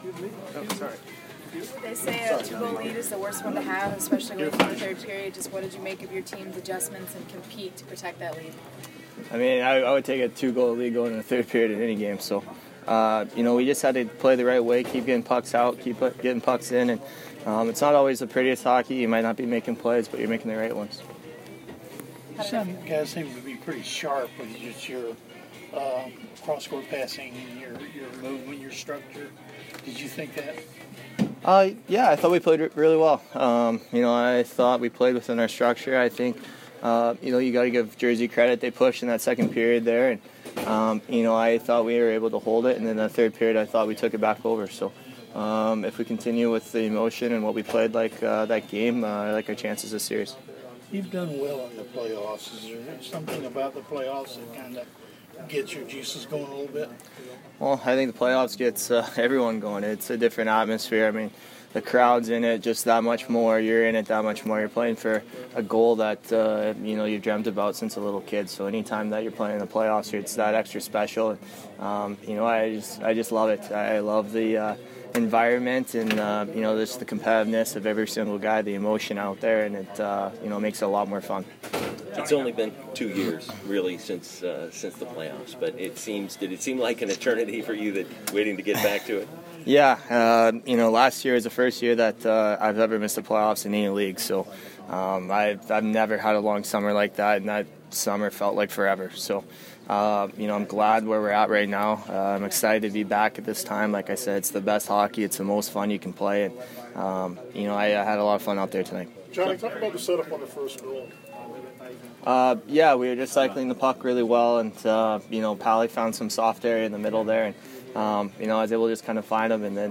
Tyler Johnson post-game 4/12